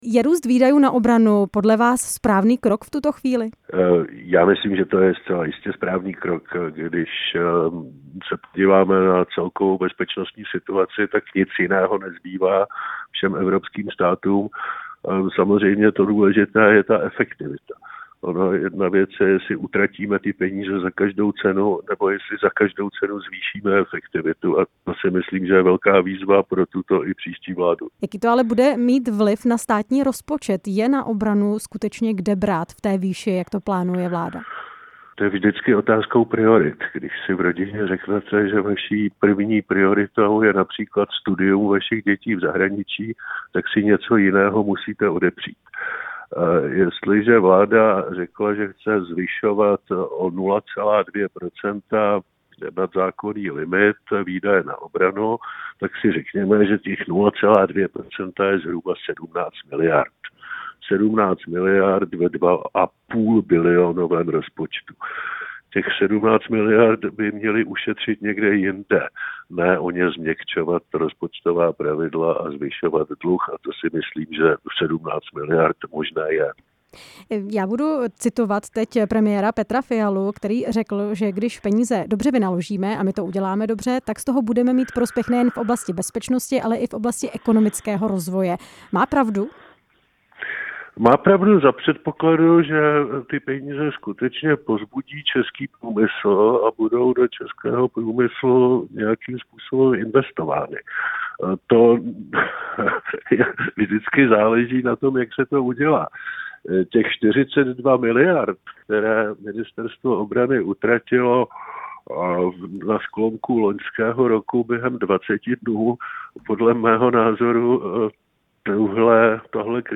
Růst výdajů na obranu jsme ve vysílání Rádia Prostor probírali s exministrem financí Miroslavem Kalouskem.
Rozhovor s exministrem financí Miroslavem Kalouskem